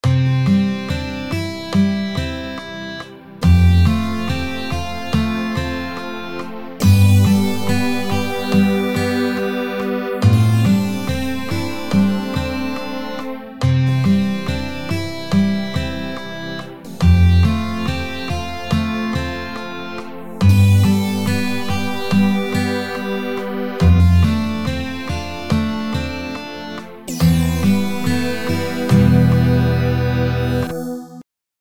Mit Synt: